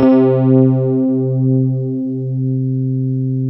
RHODES C2.wav